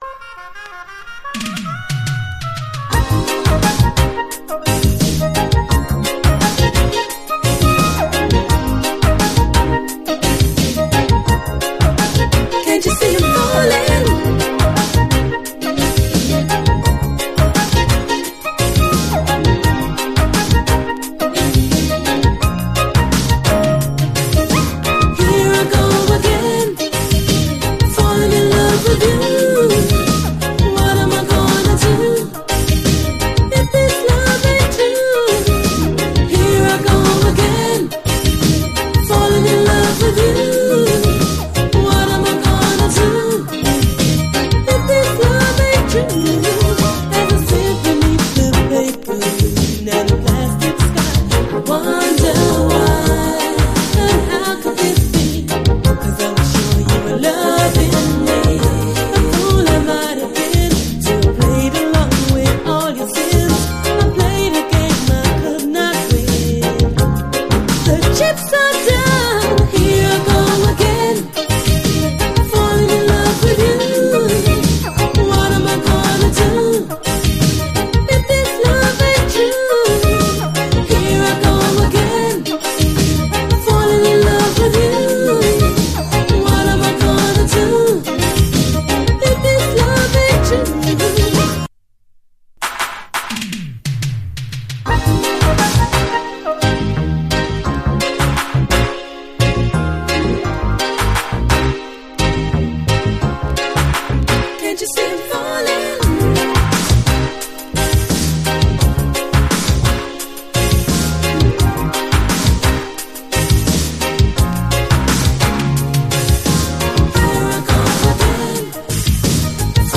という女性コーラスのリフレインが非常に耳に残る
」、ブリブリに真っ黒いベースが主張する「